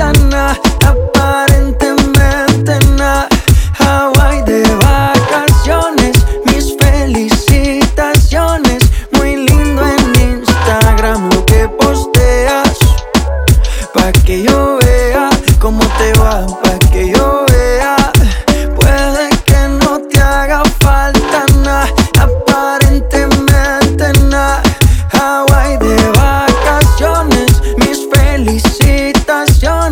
Urbano latino